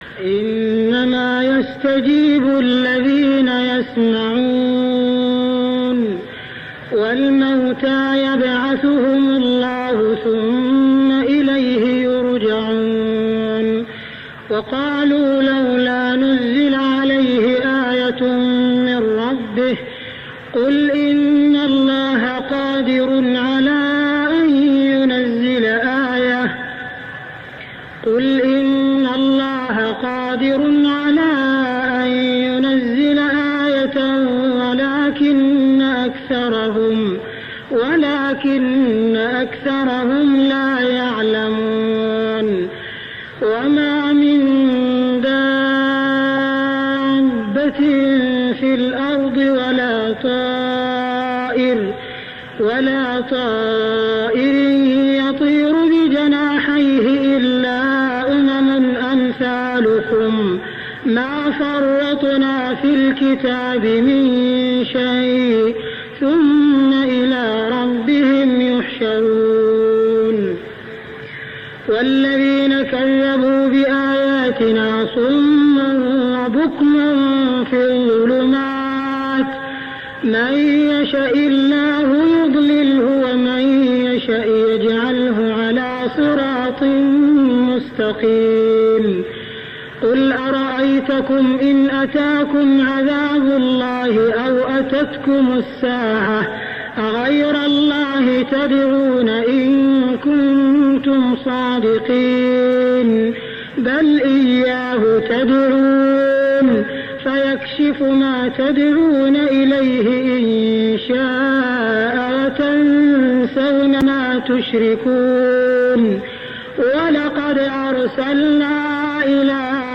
صلاة التراويح ليلة 7-9-1409هـ سورة الأنعام 36-110 | Tarawih prayer Surah Al-An'am > تراويح الحرم المكي عام 1409 🕋 > التراويح - تلاوات الحرمين